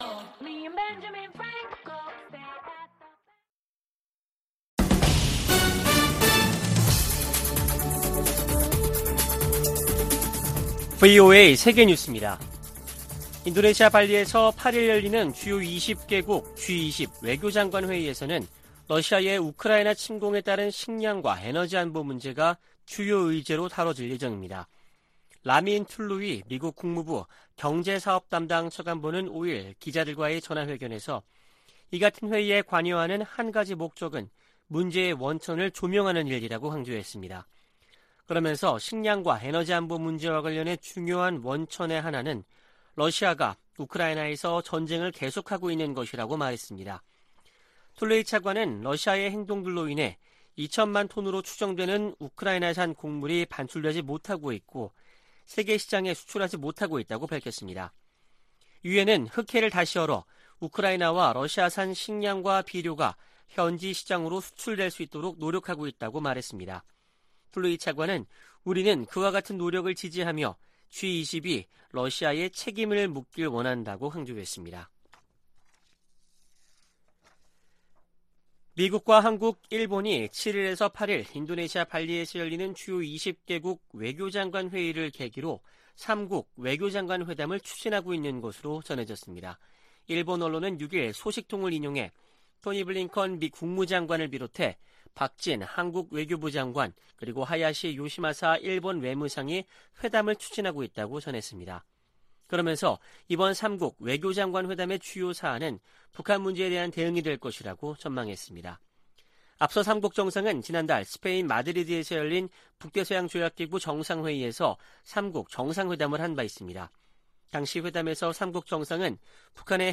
VOA 한국어 아침 뉴스 프로그램 '워싱턴 뉴스 광장' 2022년 7월 7일 방송입니다. 바이든 정부의 동맹 강화 의지는 인도태평양 지역에서 가장 뚜렷하다고 미 국무부 동아시아태평양 담당 차관보가 말했습니다. 국제원자력기구(IAEA) 사무총장이 북한 핵 문제를 해결되지 않은 집단적 실패 사례로 규정했습니다. 미 하원에서 북한 등 적국들의 ‘회색지대 전술’에 대응을 개선토록 하는 법안이 추진되고 있습니다.